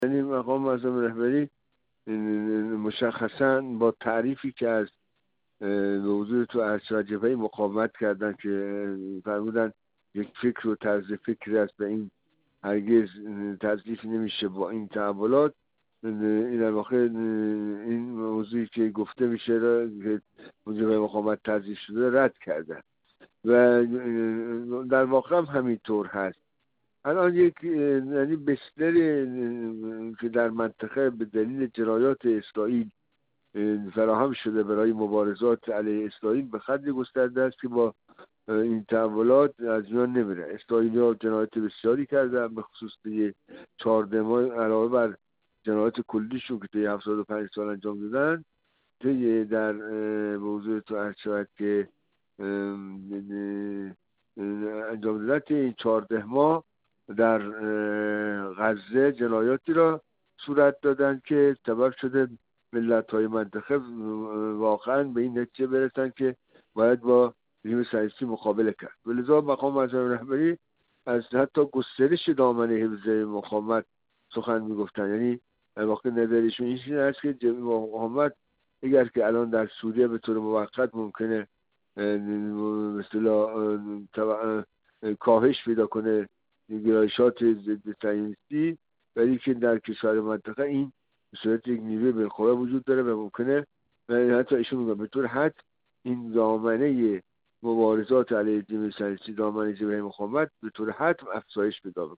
کارشناس مسائل غرب آسیا
گفت‌وگو